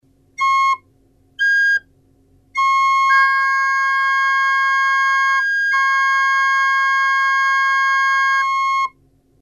1 - Accord de quinte LA4 et MI5 - (Joué sur une doublette, ce sont, sur le clavier LA2 et MI3).
Les deux notes jouées ensemble, on entend une résultante LA3